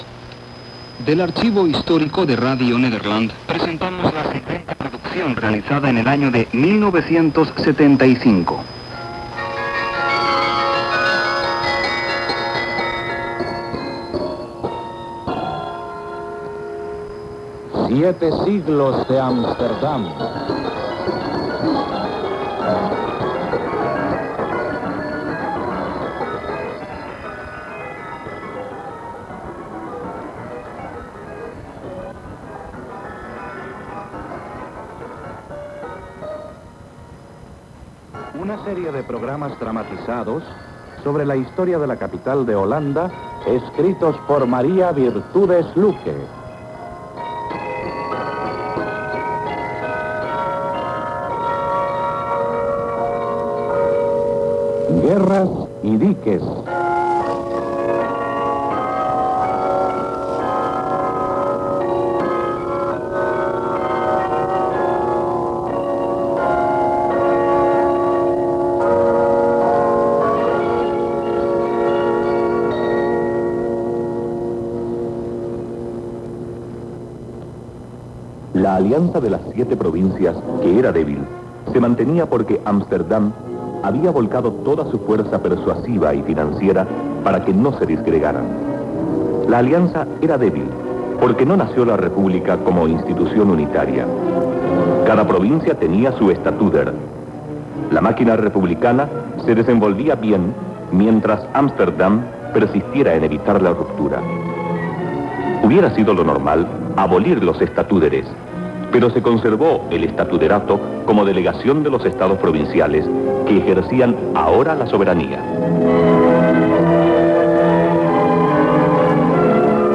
Datos de la grabación: Digitalizada de un cassette analógico de 60 minutos.